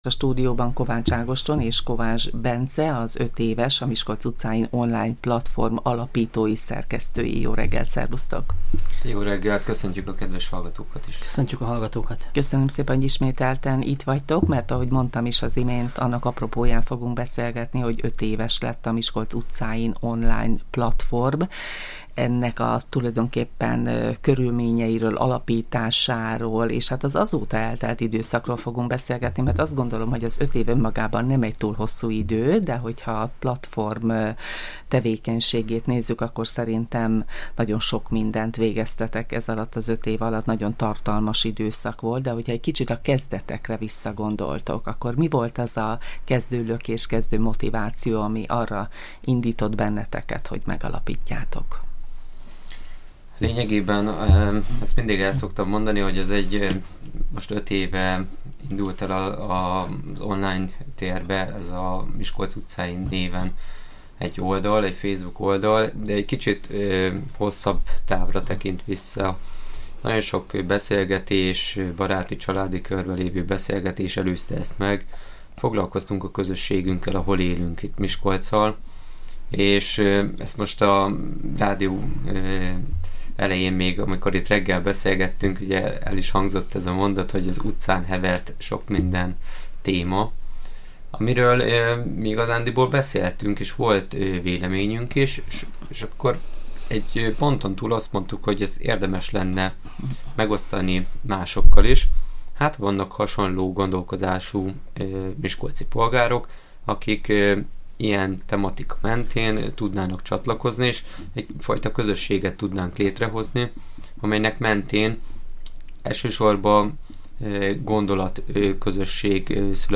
voltak a stúdiónk vendégei, akik felelevenítették az elmúlt öt év eseményeit és a kezdeti elképzeléseket.